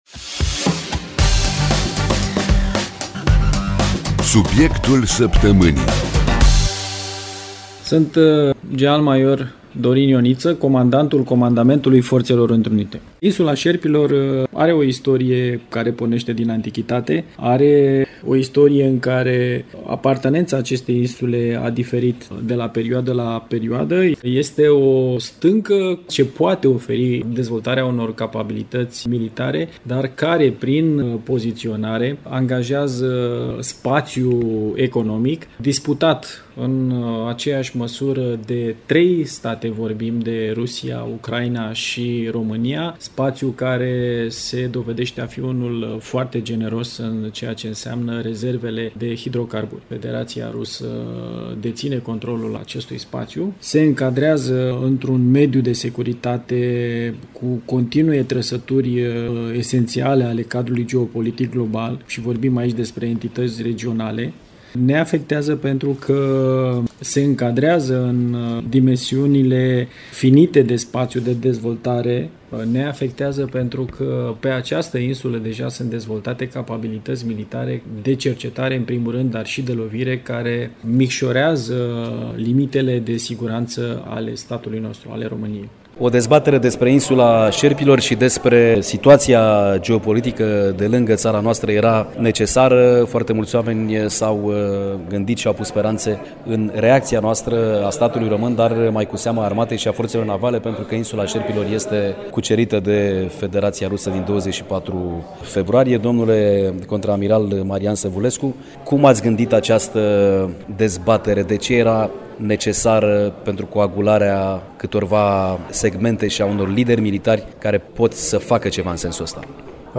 De aceea, în Aula Universității Naționale de Apărare „Carol I” din București s-a desfășurat un seminar organizat de Comandamentul Forțelor Întrunite, în cadrul căruia au fost dezbătute teme majore legate de controlul asupra Insulei Șerpilor și de situația de securitate de la Marea Neagră.